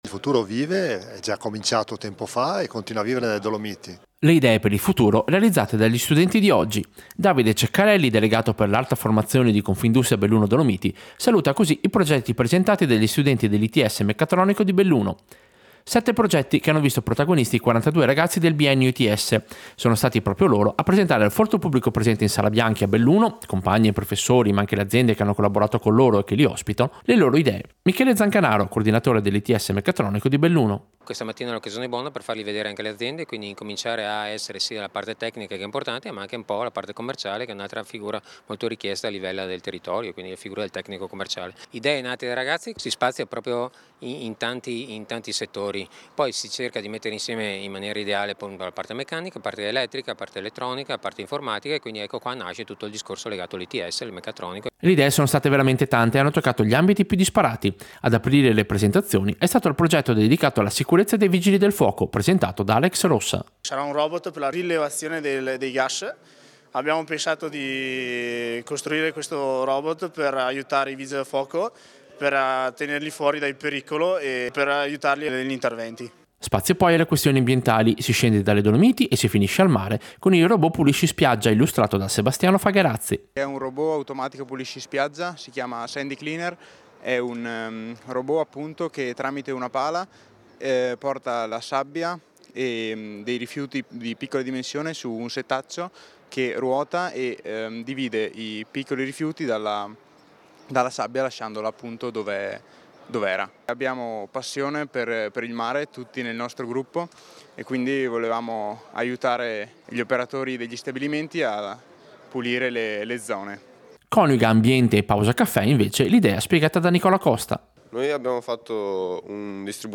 Servizio-ITS-Meccatronico-progetti-2025.mp3